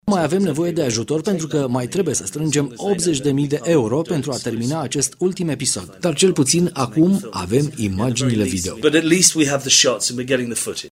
În urmă cu două zile, echipa de filmare s-a aflat la Brașov, ocazie cu care ne-a vorbit despre faza în care se află acum filmările.